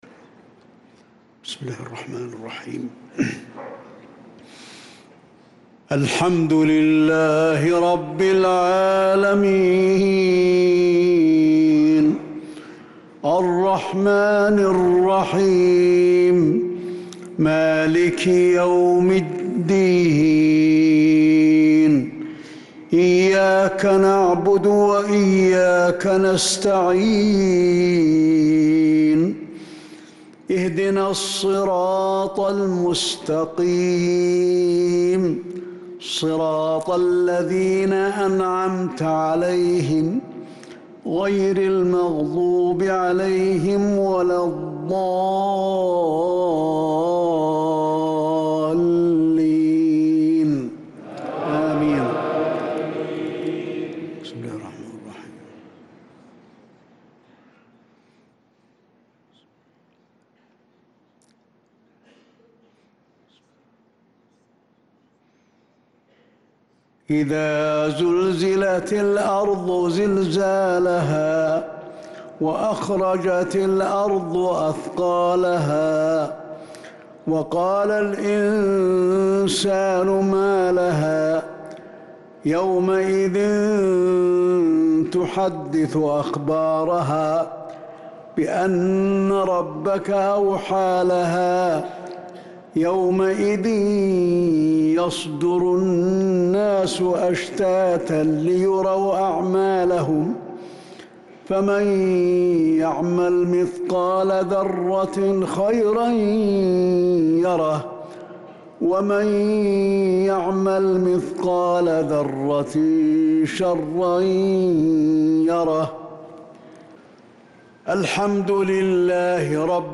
صلاة المغرب للقارئ علي الحذيفي 28 شوال 1445 هـ
تِلَاوَات الْحَرَمَيْن .